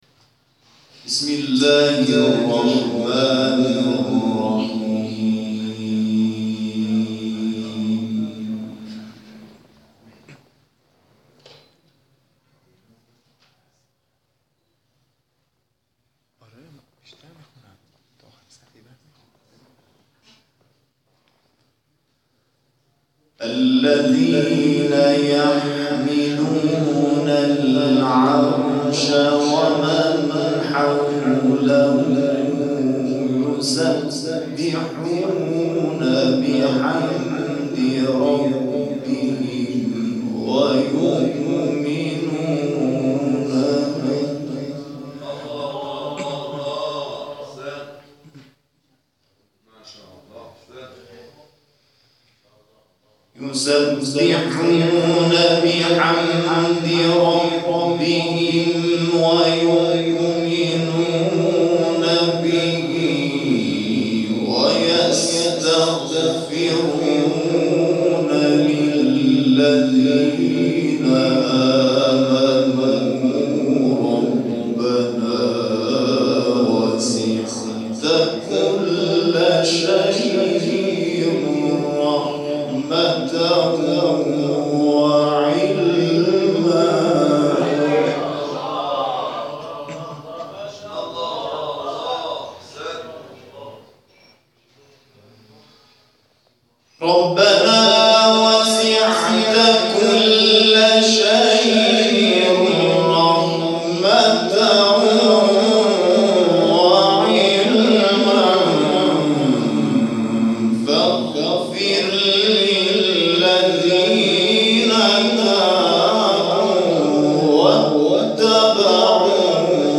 در ادامه تلاوت‌های این مراسم ارائه می‌شود.
تلاوت